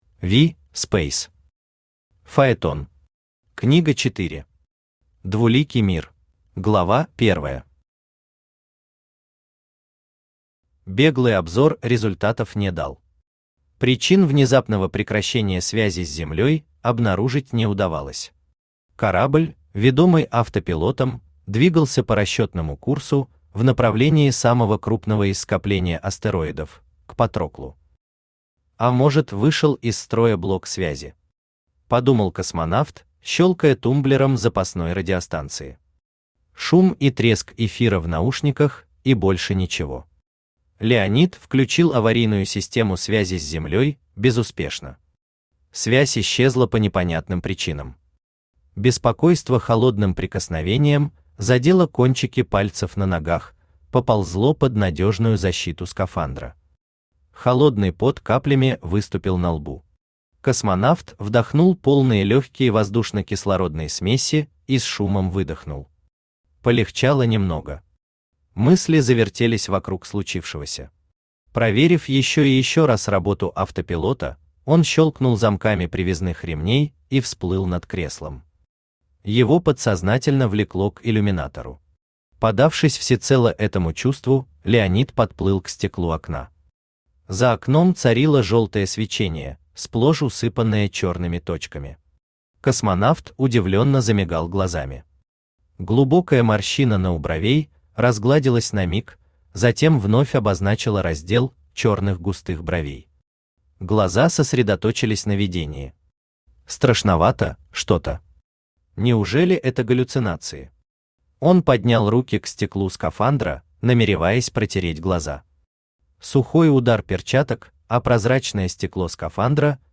Двуликий мир Автор V. Speys Читает аудиокнигу Авточтец ЛитРес.